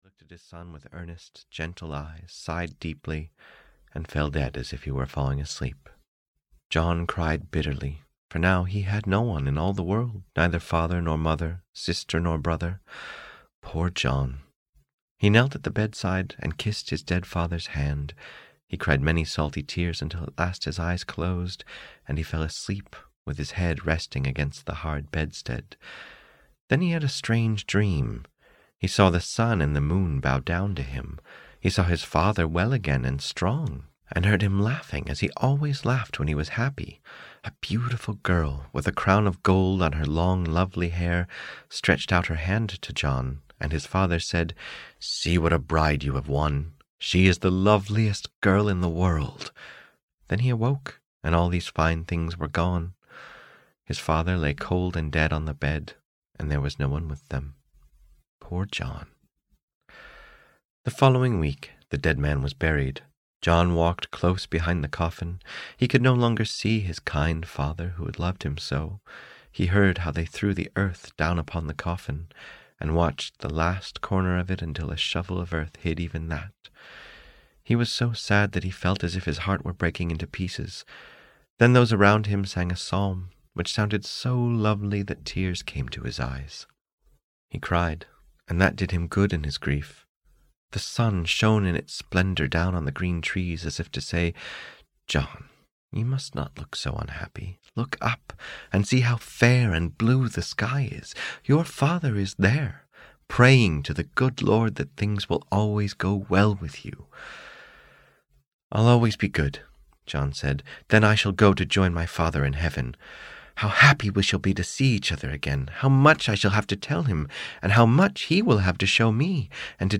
Audio knihaThe Travelling Companion (EN)
Ukázka z knihy